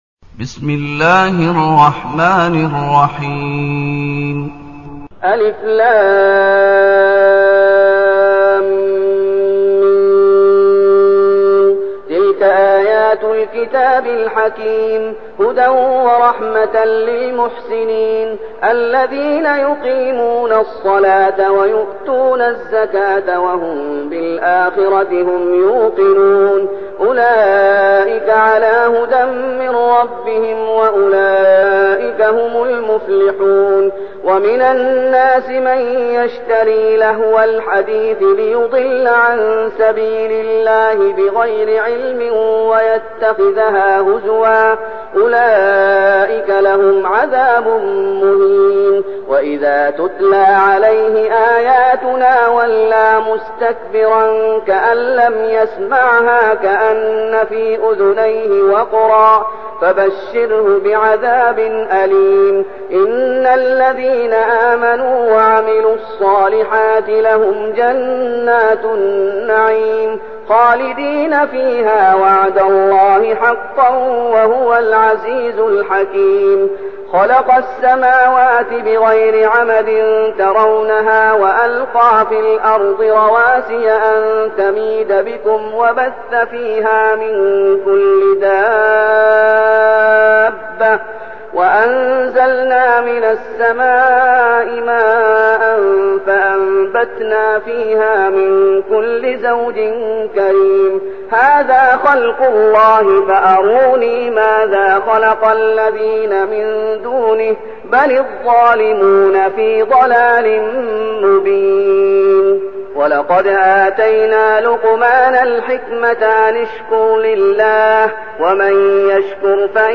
المكان: المسجد النبوي الشيخ: فضيلة الشيخ محمد أيوب فضيلة الشيخ محمد أيوب لقمان